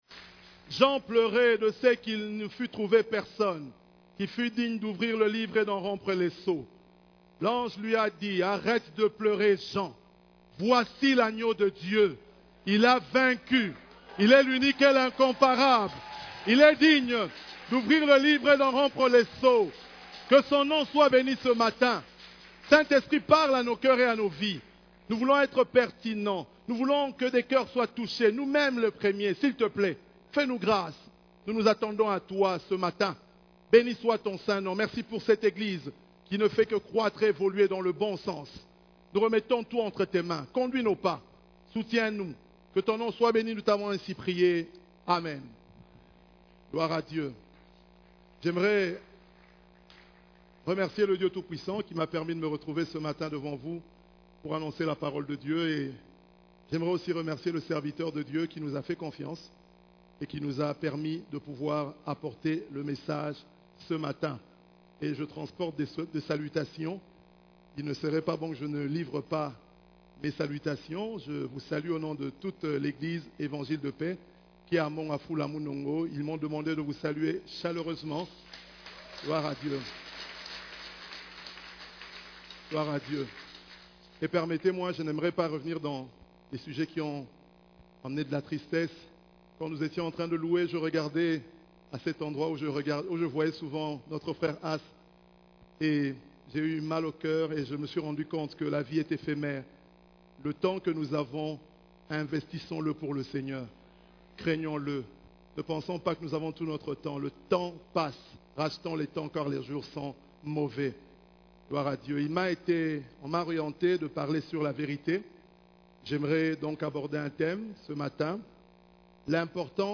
CEF la Borne, Culte du Dimanche, Serviteur, seul poste vacant dans l'Eglise